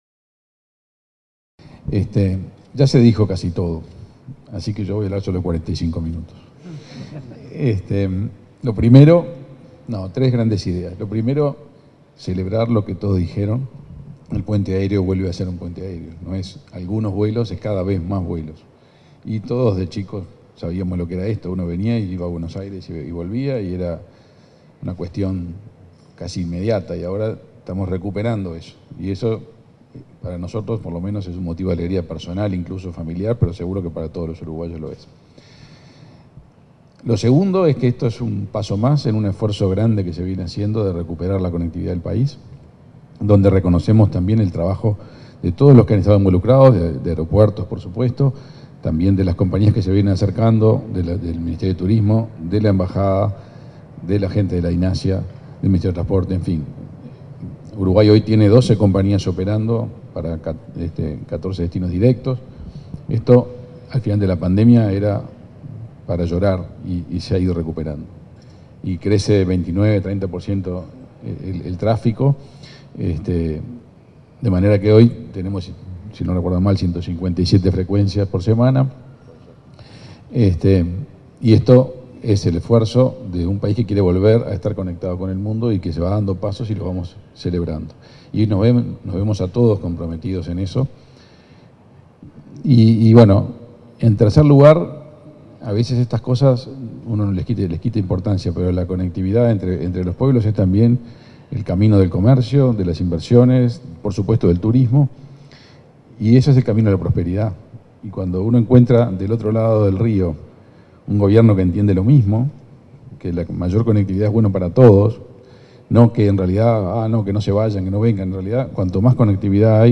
Palabras del ministro de Relaciones Exteriores, Omar Paganini
En el marco del primer día de operación del vuelo Montevideo-Buenos Aires de la compañía Jetsmart, se expresó el ministro de Relaciones Exteriores,